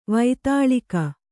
♪ vaitāḷika